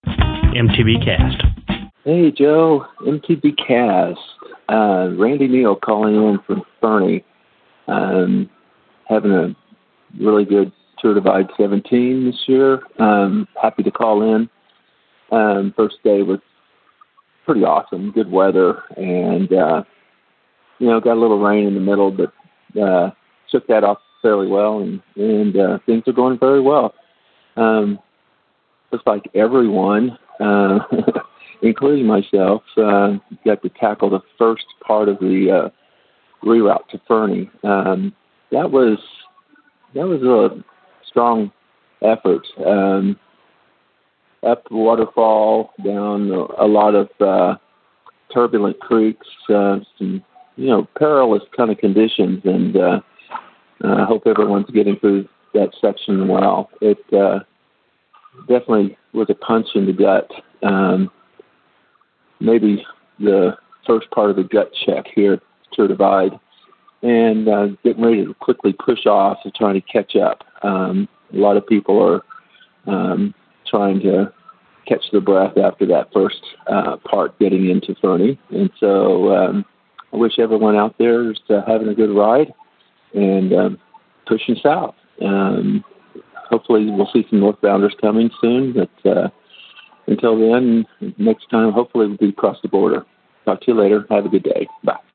Posted in Calls , TD17 Tagged bikepacking , calls , cycling , MTBCast , TD17 , ultrasport permalink